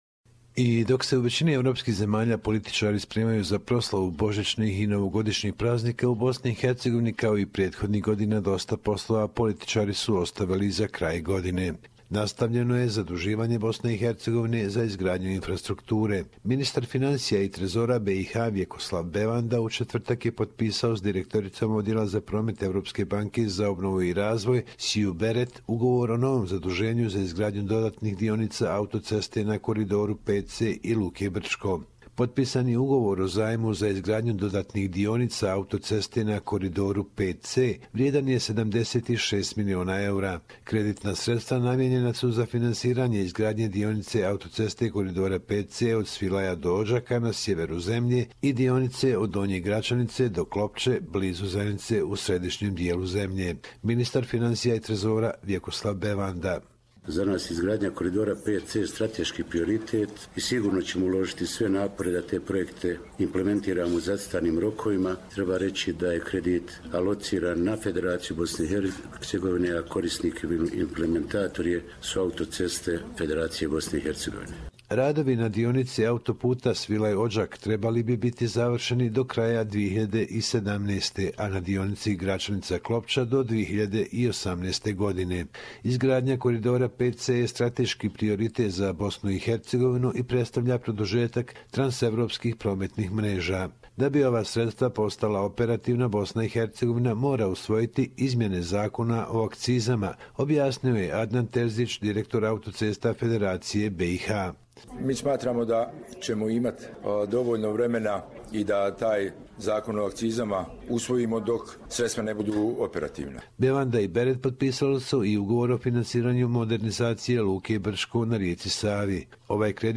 Report from Bosnia and HerzegovinaDecember 23, 2016